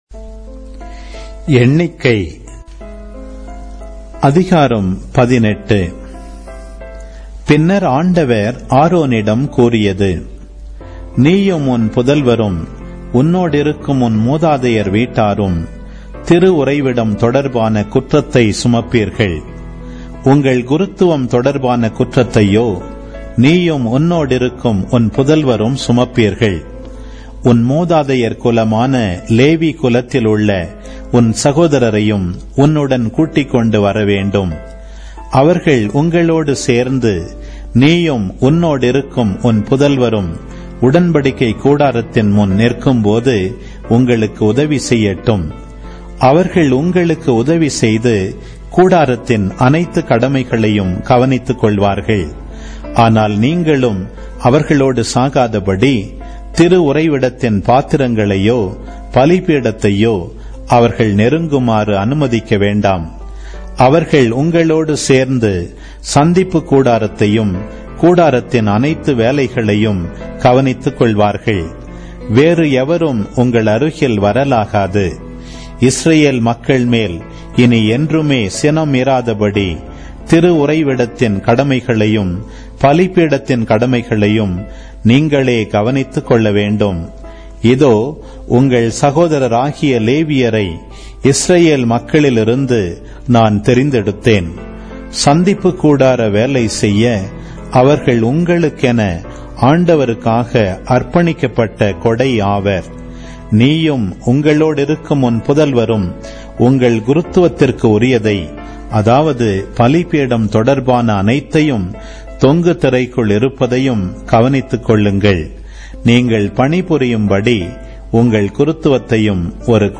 Audio Bible